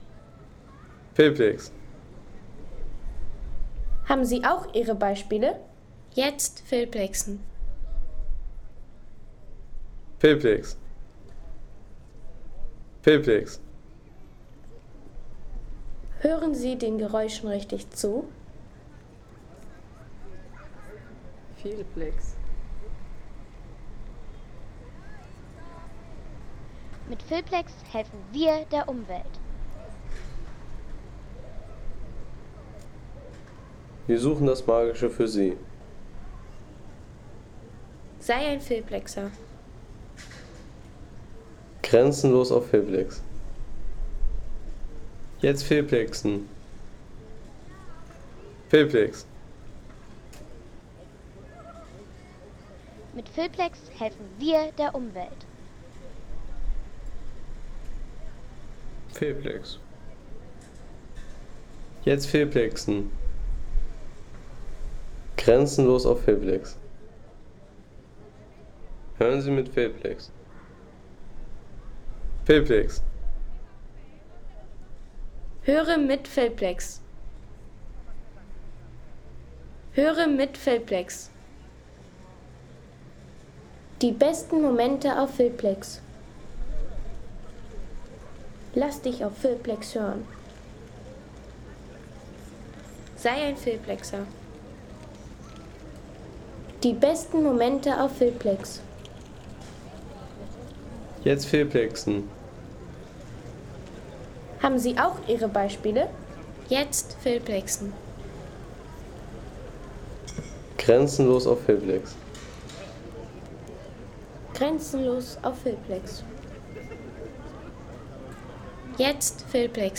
Südermarkt, Flensburg
Südermarkt, Flensburg Home Sounds Landschaft Städte Südermarkt, Flensburg Seien Sie der Erste, der dieses Produkt bewertet Artikelnummer: 19 Kategorien: Landschaft - Städte Südermarkt, Flensburg Lade Sound.... Auf dem Südermarkt in Flensburg begrüßt die St. Nikolai Kirche um 1 ... 3,50 € Inkl. 19% MwSt.